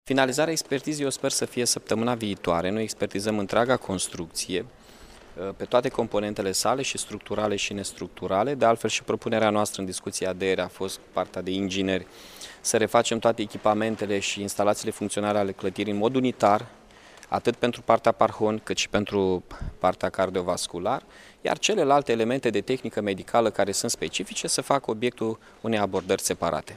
Expertiza clădirii în care funcționează Spitalul Parhon se va încheia săptămâna viitoare. Declarația a fost făcută de primarul municipiului Iași, Mihai Chirica